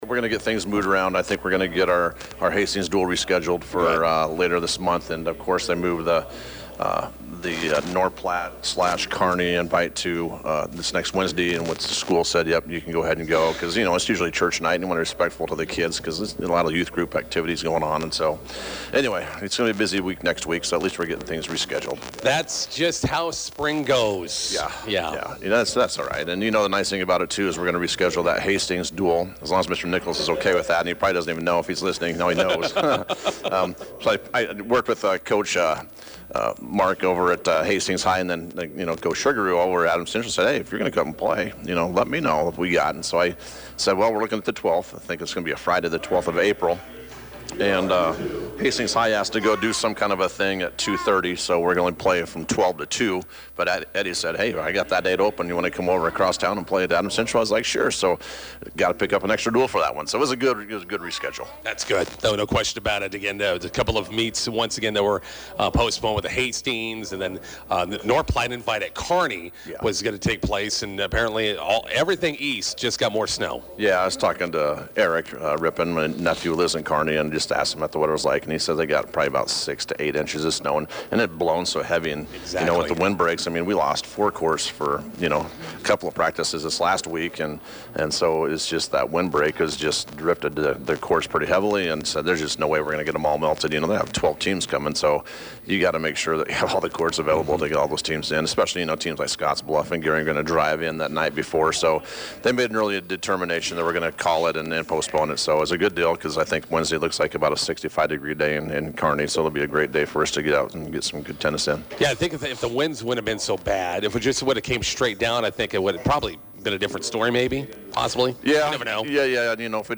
INTERVIEW: Bison Tennis finally back on the courts, hosting Gothenburg on senior day at the Bison Tennis Courts.